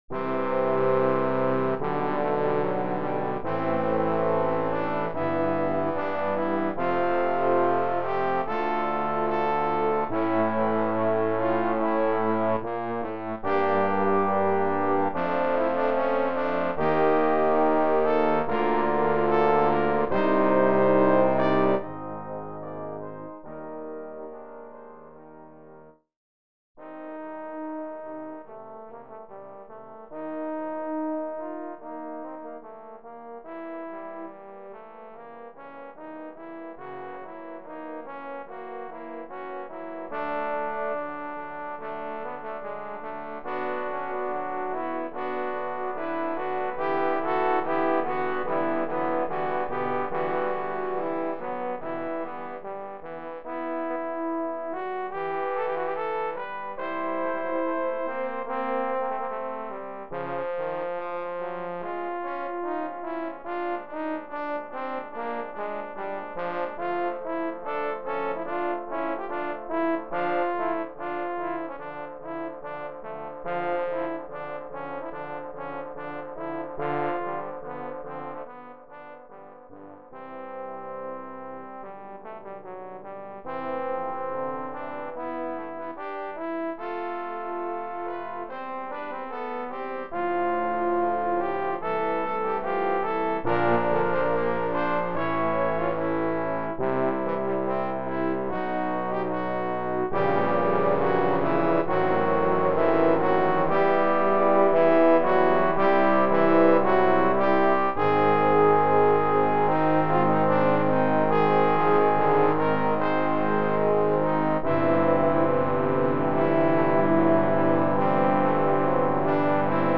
Symphony No. 5 - Adagio for 8 parts